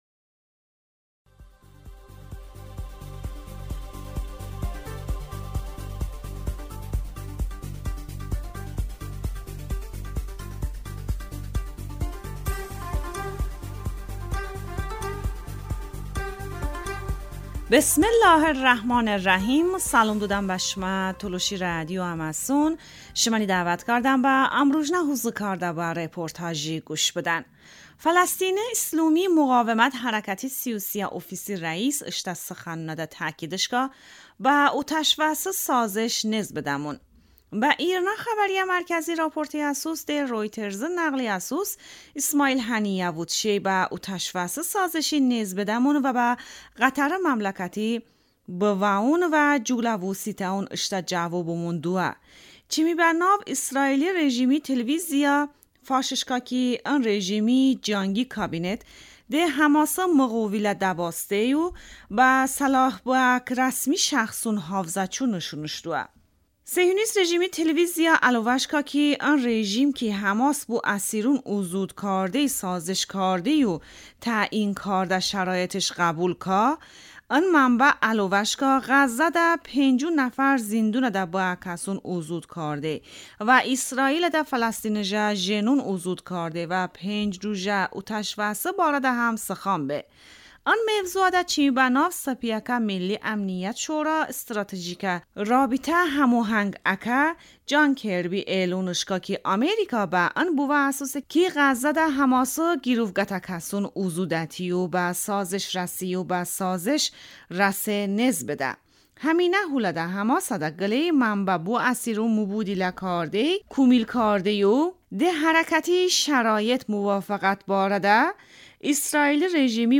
Tolışi rədiyo ım rujnə reportaj